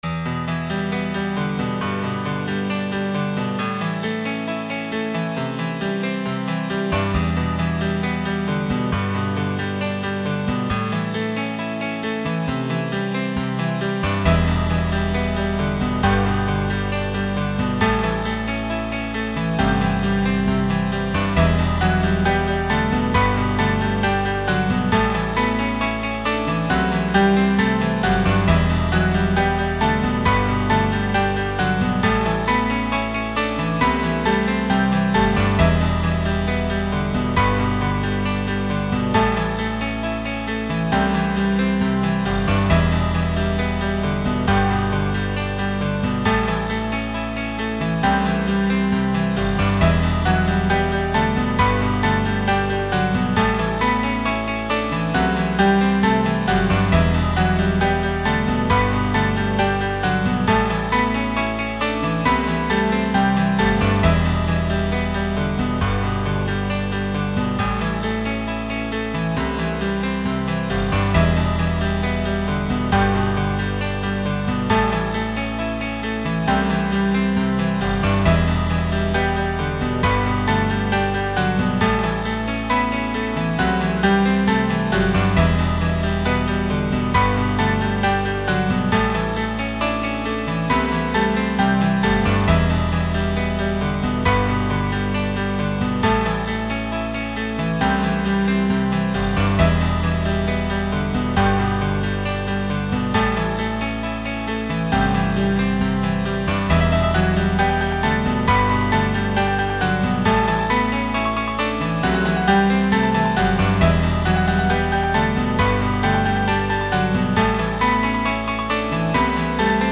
(Em) (G) (A) (C Cadd9)